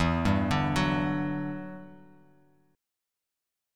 Em9 Chord
Listen to Em9 strummed